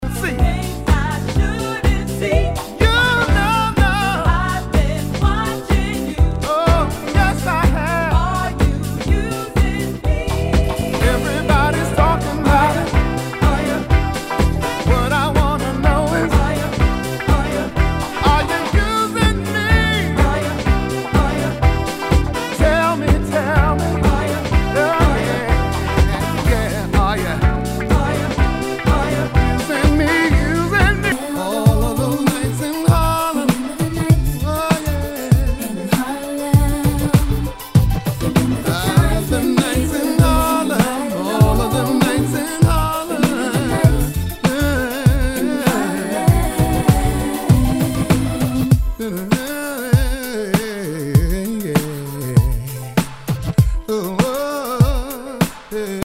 HOUSE/TECHNO/ELECTRO
ヴォーカル・ハウス / ダウンテンポ！
全体にチリノイズが入ります